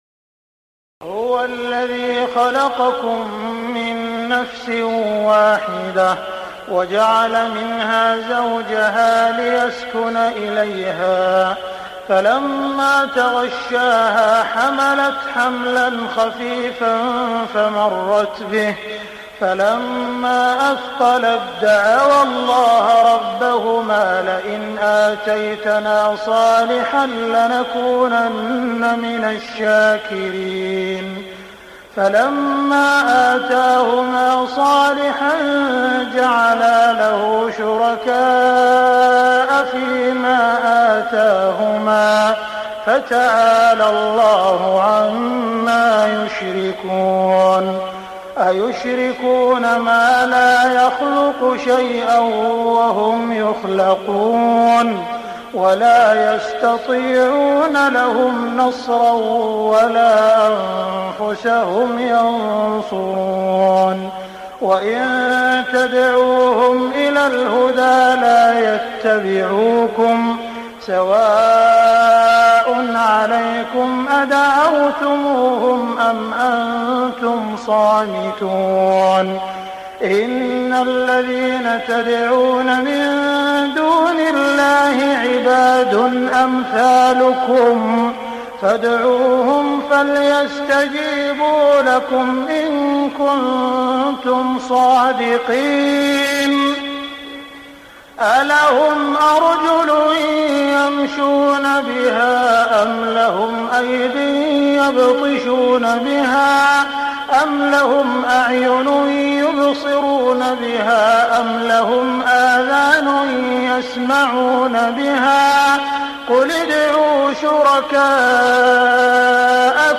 تهجد ليلة 29 رمضان 1418هـ من سورتي الأعراف (189-206) و الأنفال (1-40) Tahajjud 29 st night Ramadan 1418H from Surah Al-A’raf and Al-Anfal > تراويح الحرم المكي عام 1418 🕋 > التراويح - تلاوات الحرمين